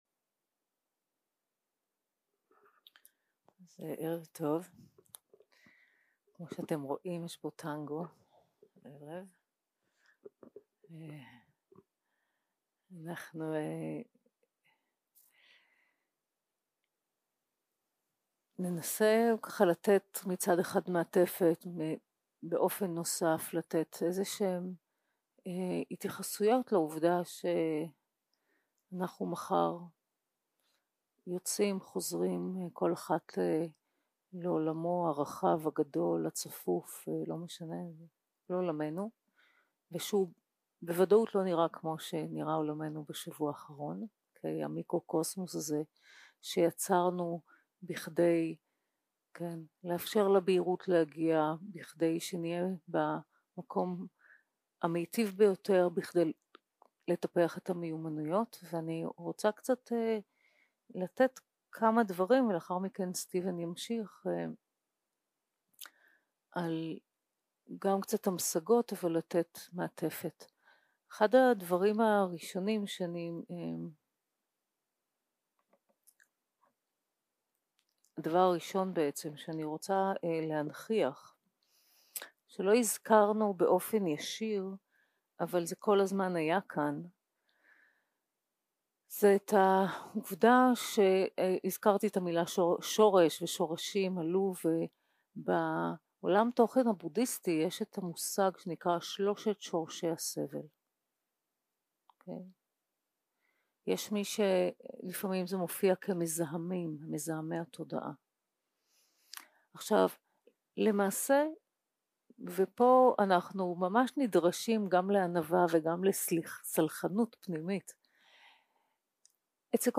יום 6 - הקלטה 16 - ערב - שיחת דהרמה - Ways to expand the heart and soul 0:00 0:00 סוג ההקלטה: Dharma type: Dharma Talks שפת ההקלטה: Dharma talk language: Hebrew